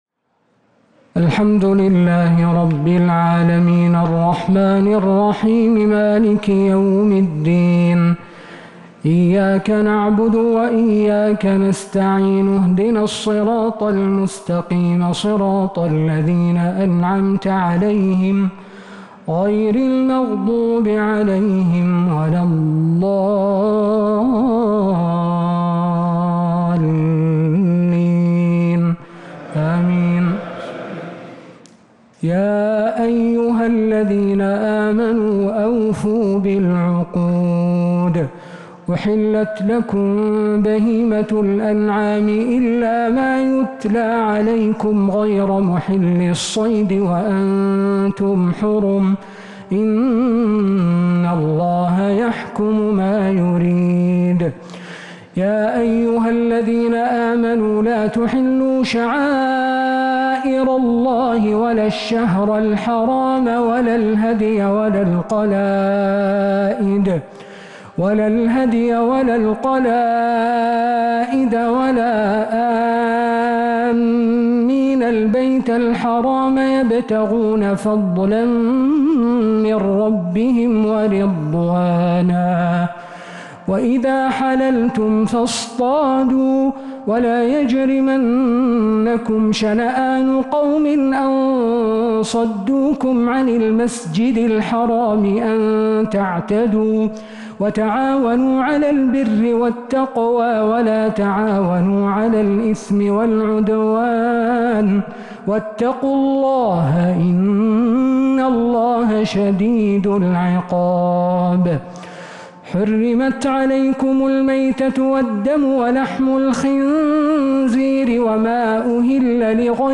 تراويح ليلة 8 رمضان 1447هـ من سورة المائدة {1-40} Taraweeh 8th night Ramadan 1447H Surah Al-Maidah > تراويح الحرم النبوي عام 1447 🕌 > التراويح - تلاوات الحرمين